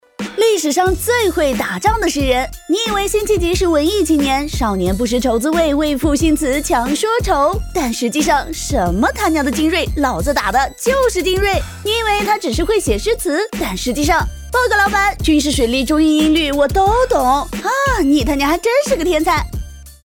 飞碟说-女68-风趣.mp3